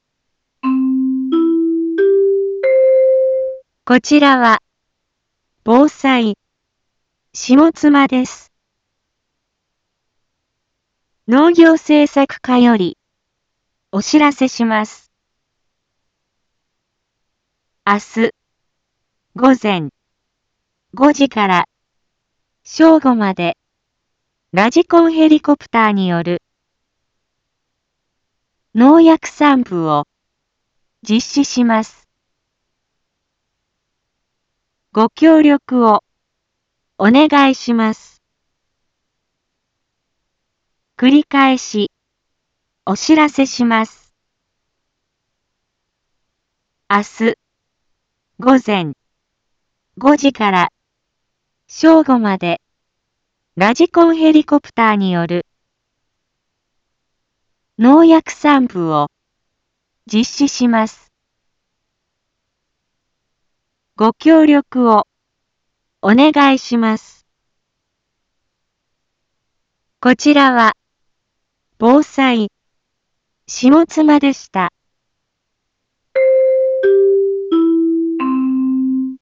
一般放送情報
Back Home 一般放送情報 音声放送 再生 一般放送情報 登録日時：2023-05-01 12:31:22 タイトル：麦のﾗｼﾞｺﾝﾍﾘによる防除（大宝） インフォメーション：こちらは、防災、下妻です。